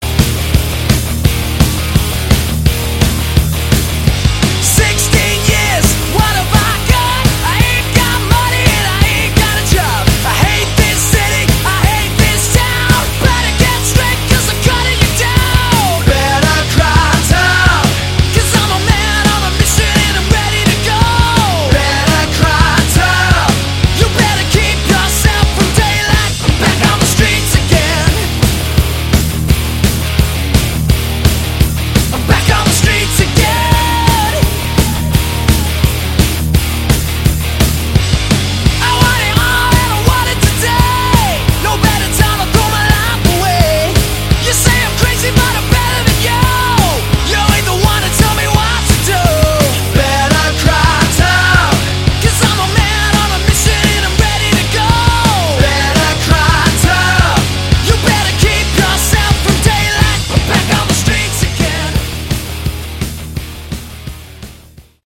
Category: Hard Rock
vocals
bass
guitar
drums